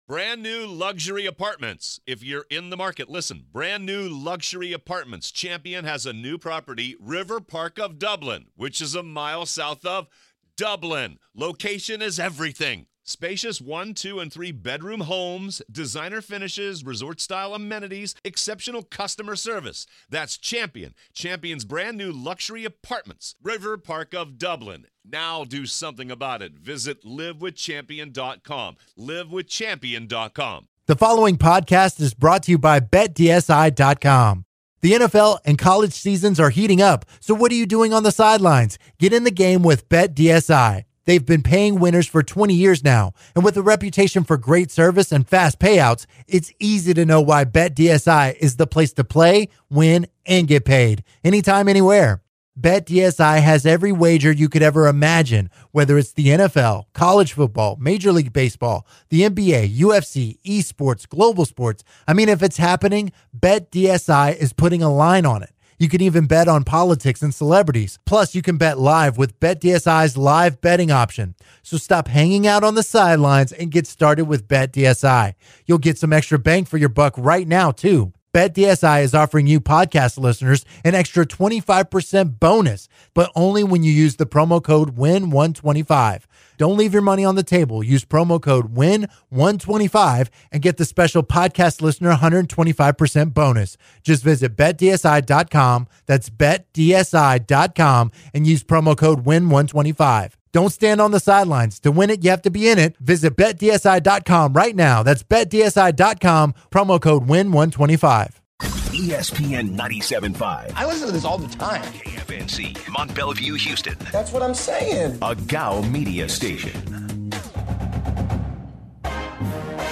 via phone
take calls from listeners